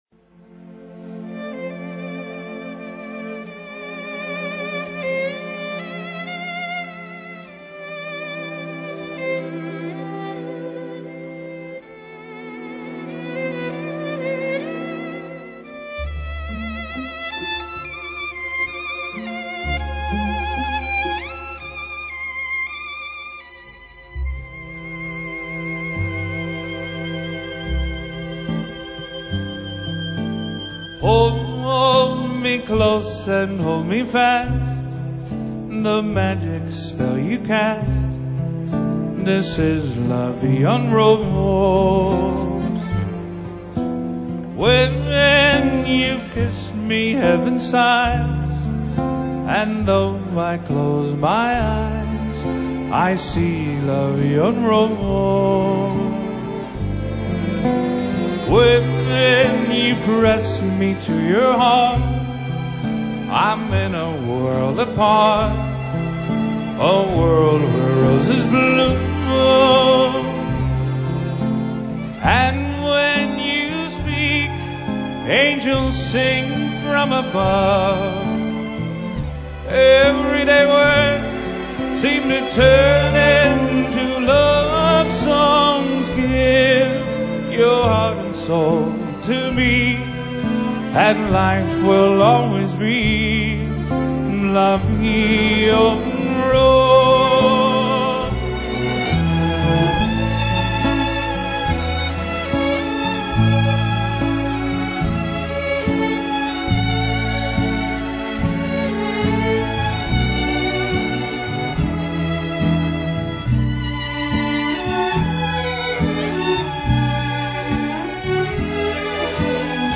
邁阿密吉他歌手
展現出視野廣闊的音樂寫景功力